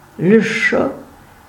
Lessoc (Freiburger Patois
Frp-greverin-Lecho.ogg.mp3